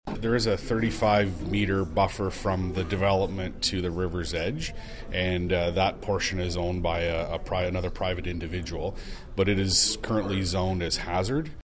In an interview with Quinte News following the meeting, Committee Chair Councillor Paul Carr commented on the distance to the river.